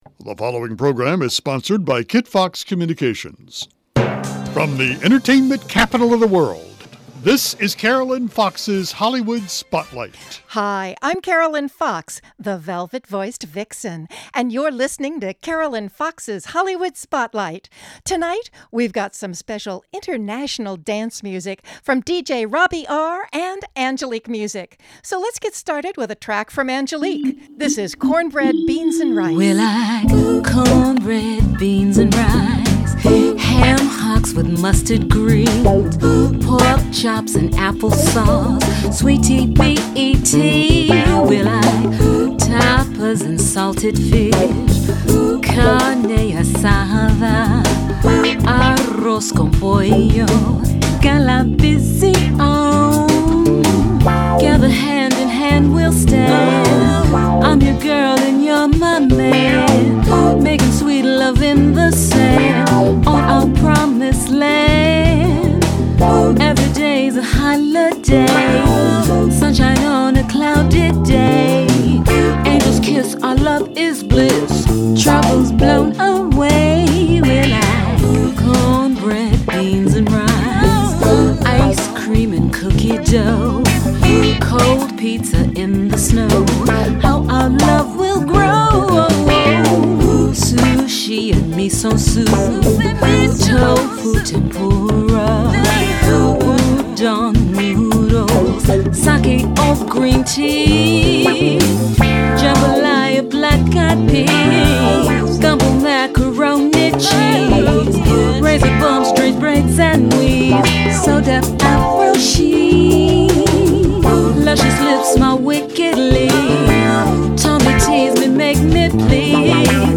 If you didn’t hear the broadcast of the syndicated radio show